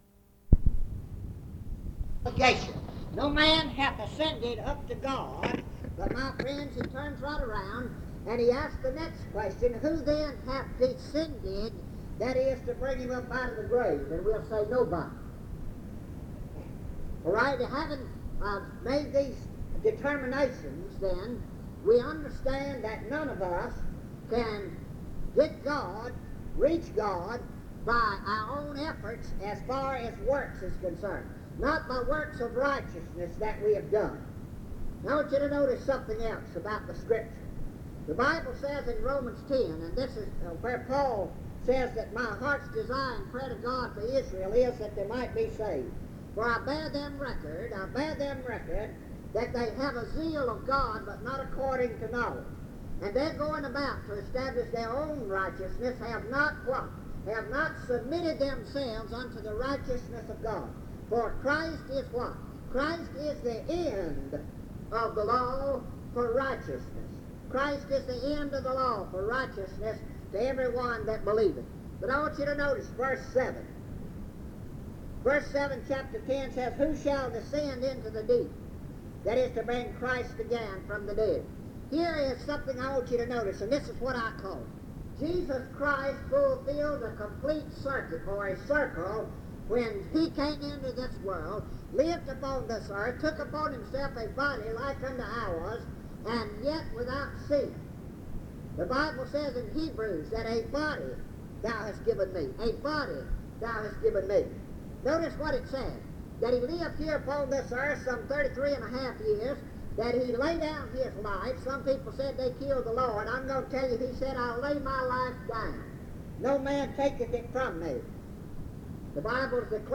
Primitive Baptists
Martinsville (Va.)